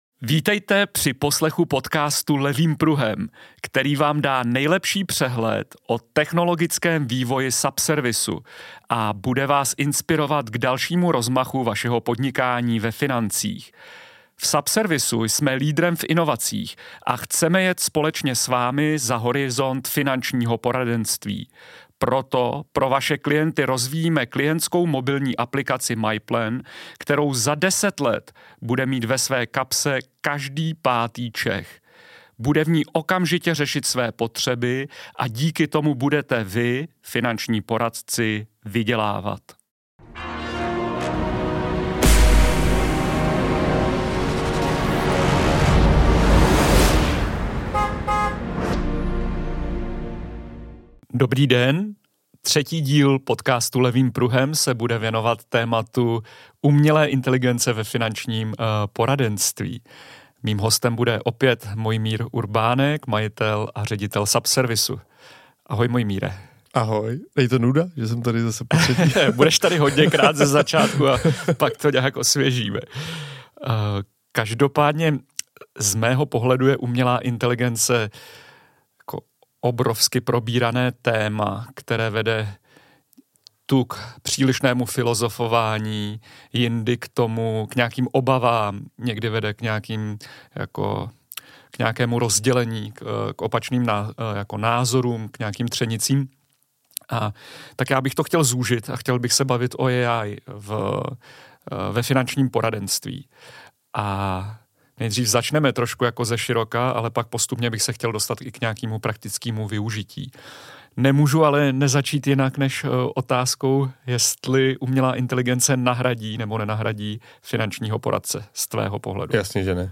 Zkrátka rozhovor o umělé inteligenci ve finančním poradenství.